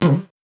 Index of /musicradar/8-bit-bonanza-samples/VocoBit Hits
CS_VocoBitC_Hit-09.wav